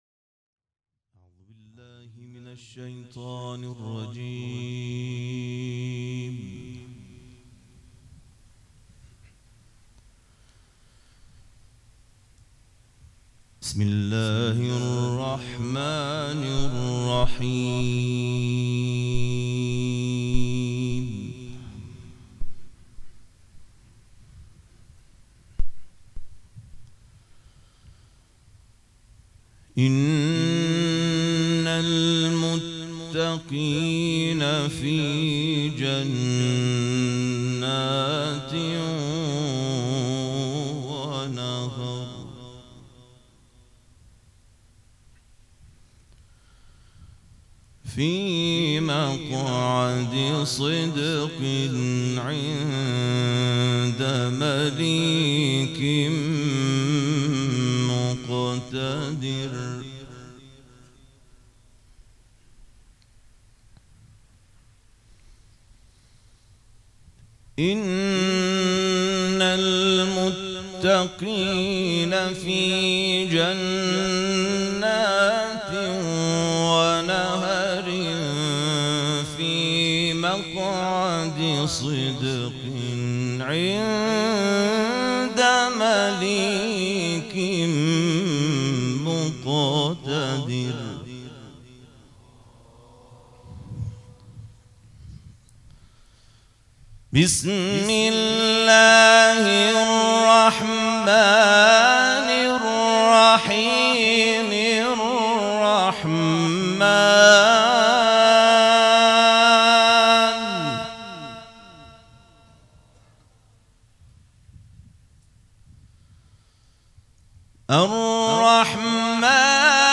قرائت قرآن
قرائت قرآن کریم
مراسم عزاداری شب شهادت حضرت زهرا (س)